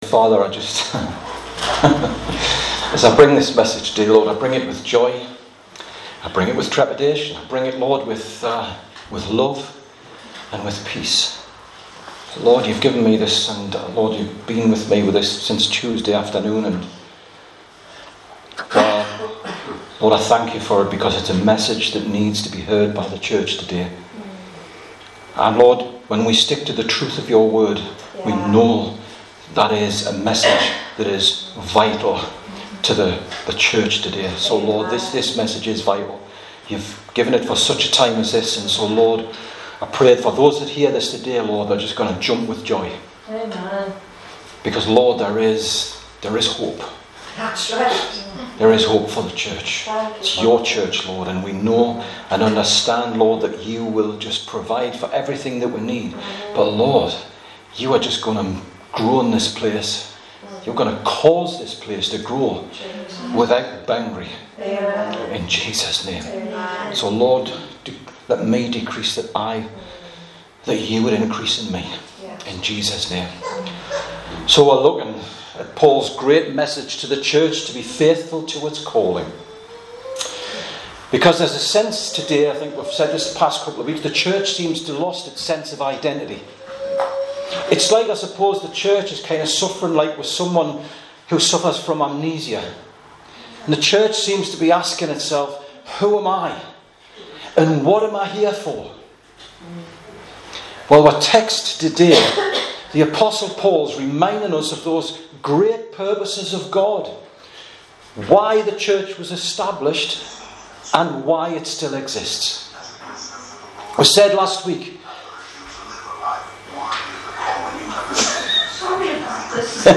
Sermons 2019